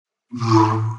ignition.wav — lightsaber powering on. Plays when a session starts.